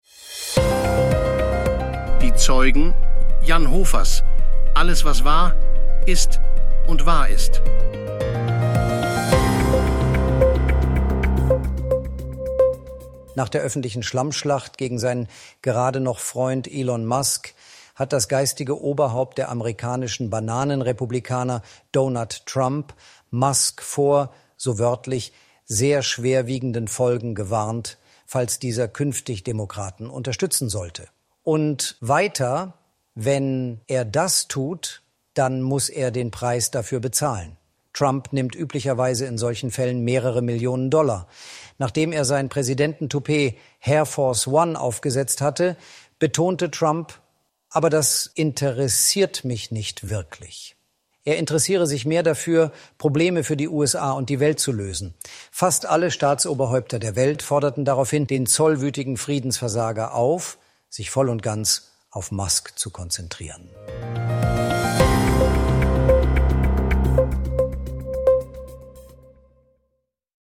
(100% KI-ssel)